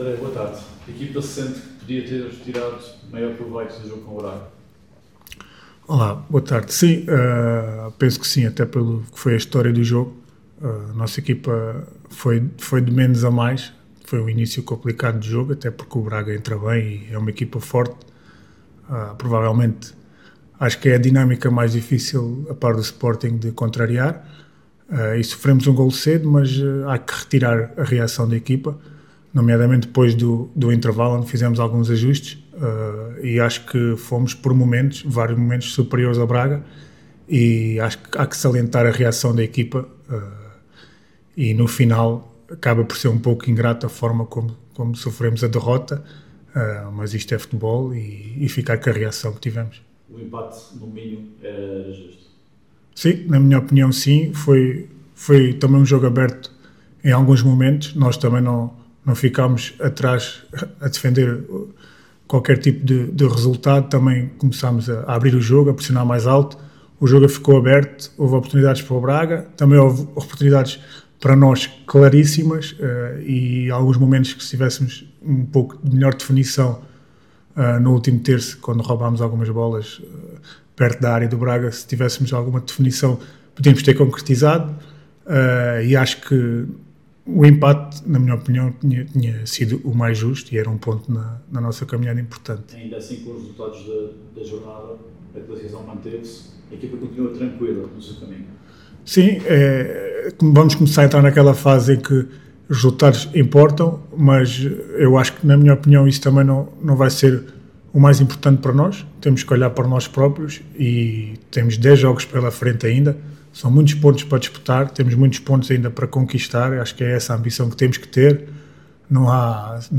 na habitual conferência de imprensa semanal.